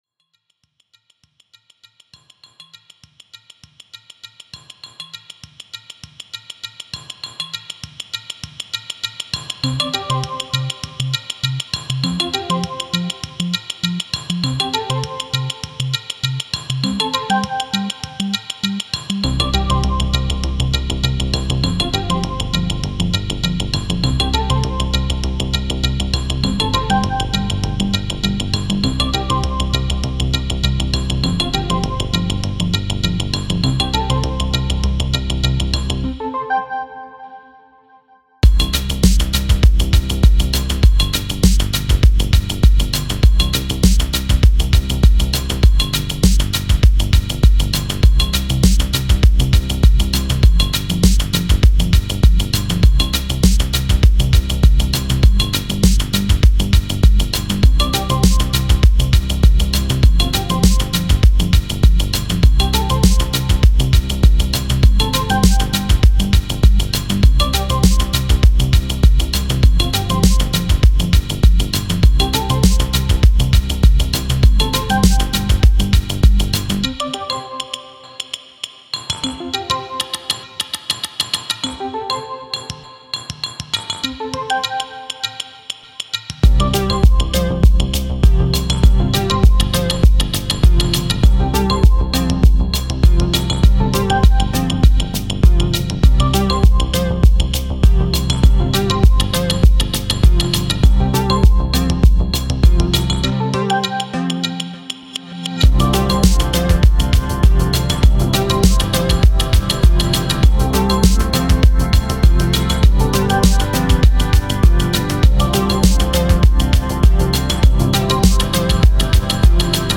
Music / Other Music
techno electro other flstudio upbeat
very well produced lil track!